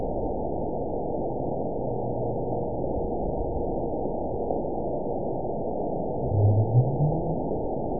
event 920408 date 03/23/24 time 23:30:30 GMT (1 year, 1 month ago) score 9.44 location TSS-AB02 detected by nrw target species NRW annotations +NRW Spectrogram: Frequency (kHz) vs. Time (s) audio not available .wav